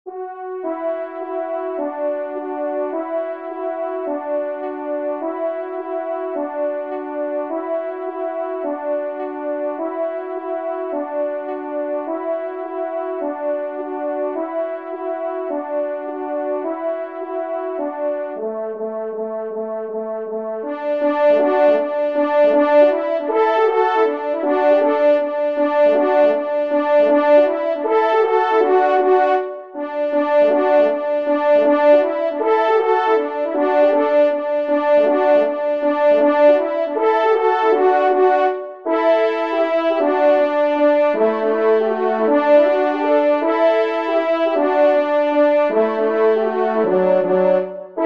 Danses polonaises
2e Trompe